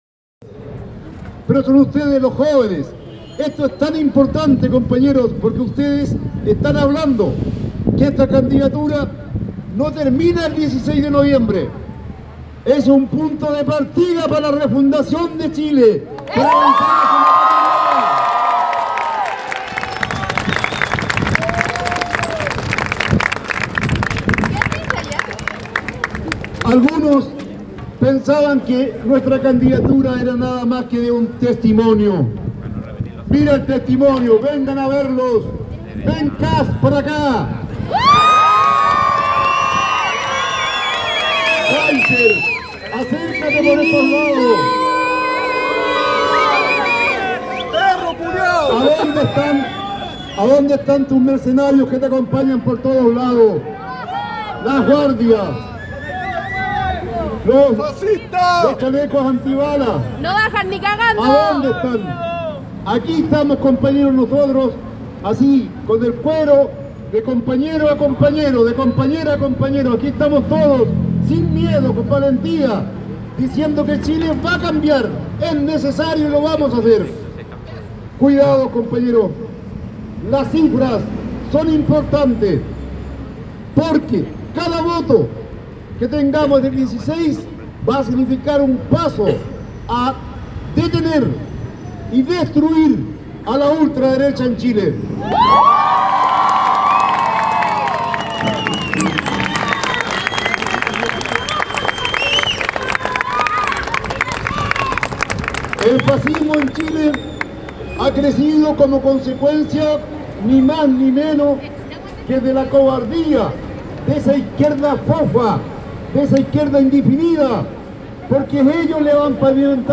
El vitoreo de un público ansioso hizo voltear todas las miradas y las manos al hombre de camisa y pantalones verde oliva.